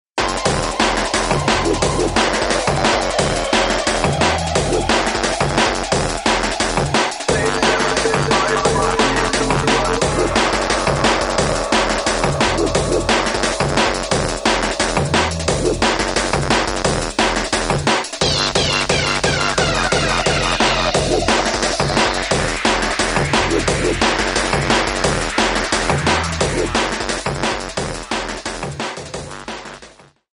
TOP >Vinyl >Drum & Bass / Jungle
TOP > Jump Up / Drum Step